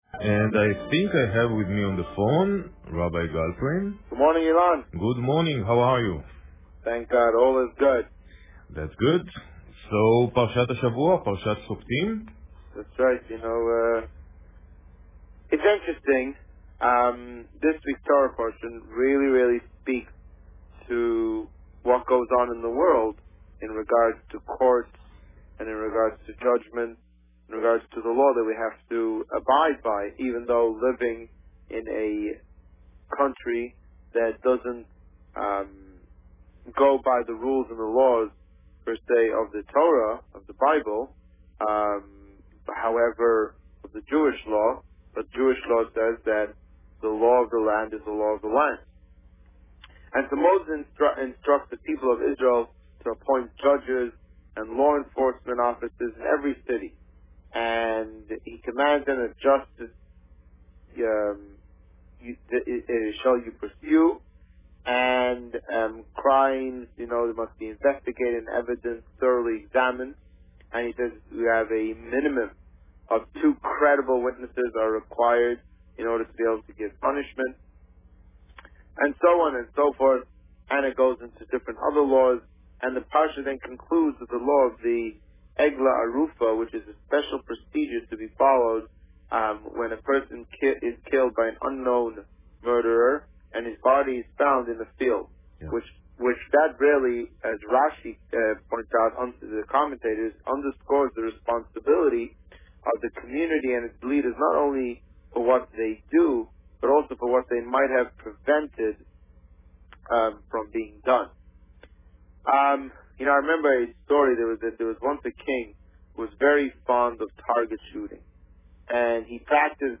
The Rabbi on Radio
Parsha Shoftim and Connecting Smiles Published: 28 August 2014 | Written by Administrator This week, the Rabbi spoke about Parsha Shoftim and a new program being introduced to Windsor - Connecting Smiles. Listen to the interview here .